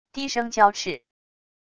低声娇斥wav音频